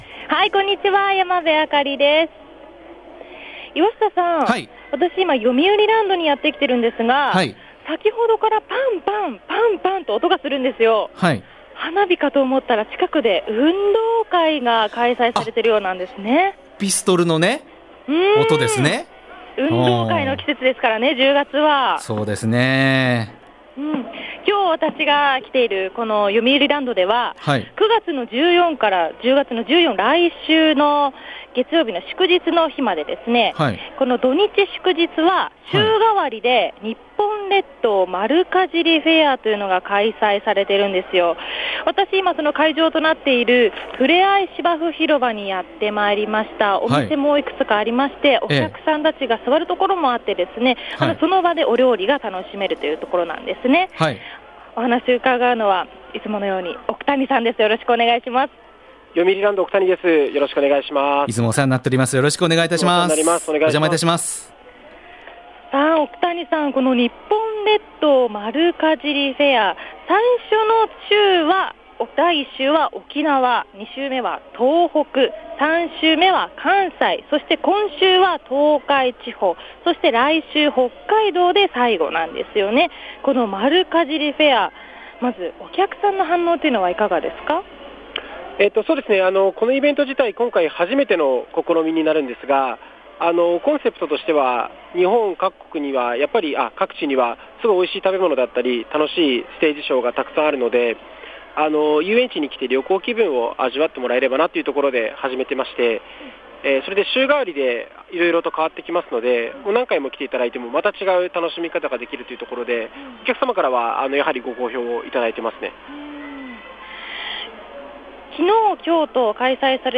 今日お邪魔したのは、よみうりランドです。